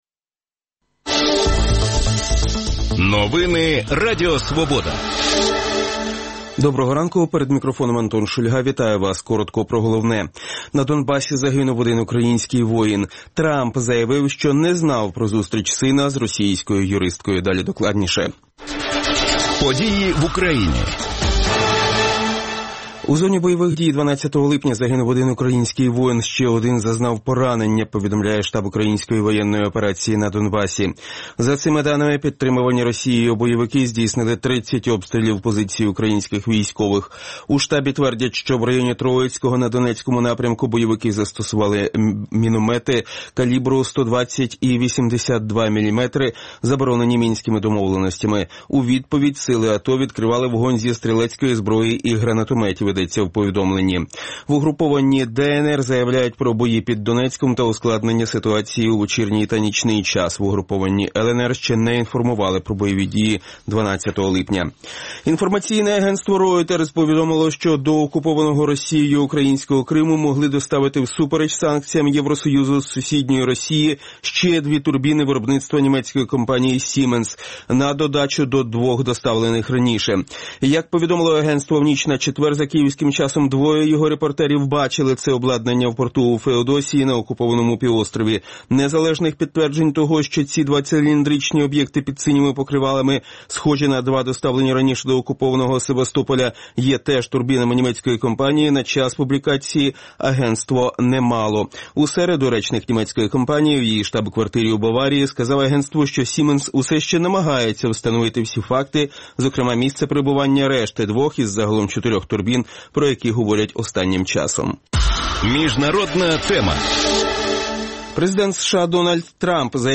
говоритиме з гостями студії.